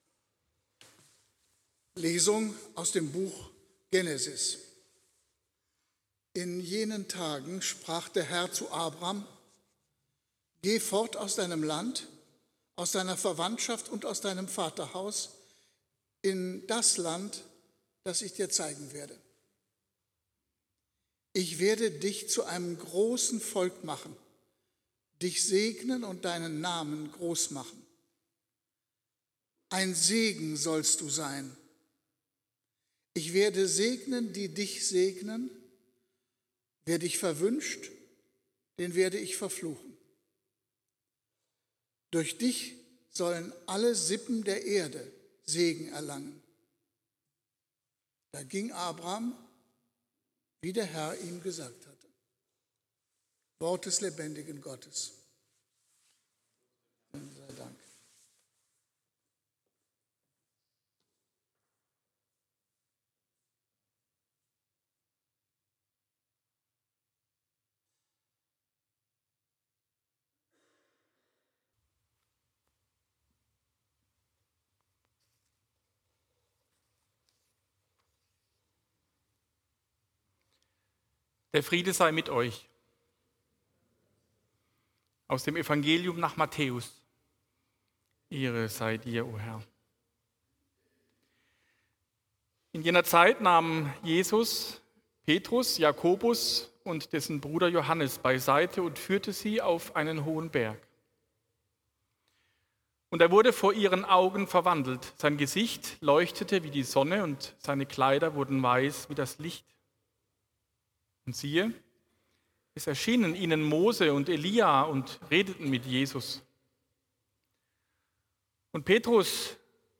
Gottesdienst vom 01. März zum Nachhören
hier kann man den heutigen Gottesdienst nachhören: